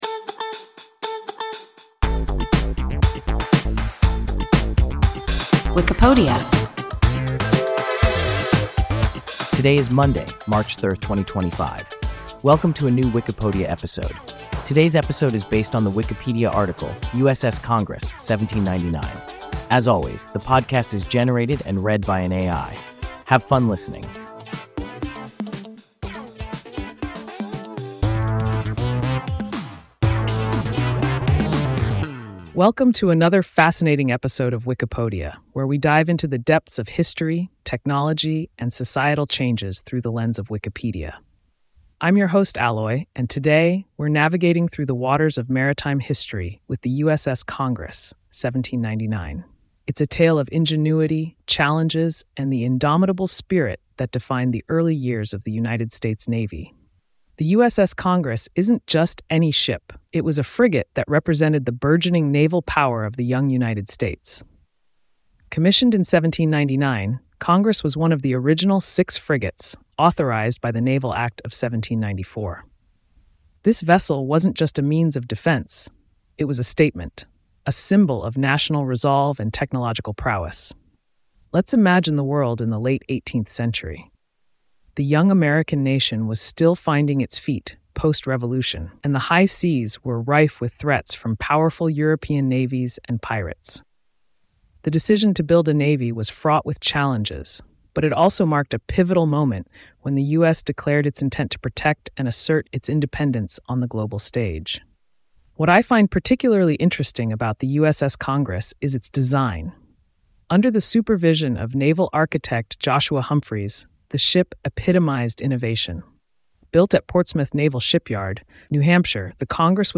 USS Congress (1799) – WIKIPODIA – ein KI Podcast